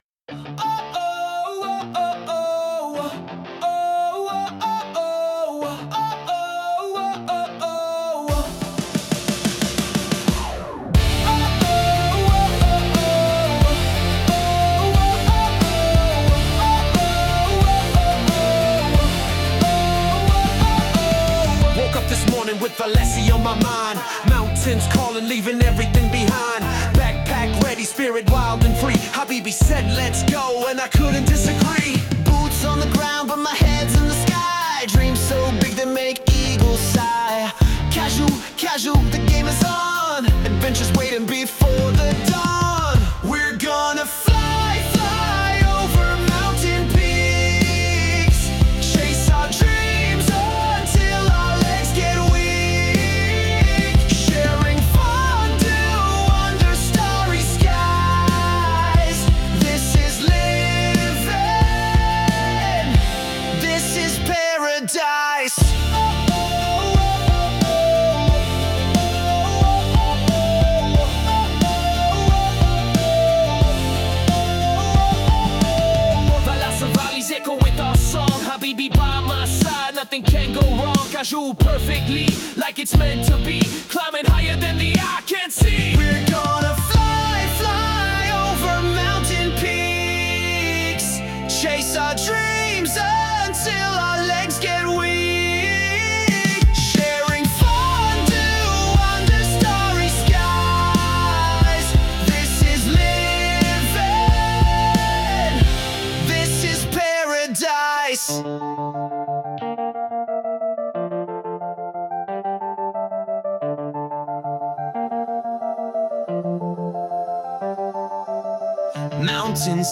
Une démonstration en direct
Après avoir utilisé Claude pour générer les paroles et Suno pour la composition, il a démontré la puissance créative des outils d’IA actuels, avec deux créations d’environ 3 minutes, mêlant rock et r’n’b, qui ont impressionné l’auditoire présent.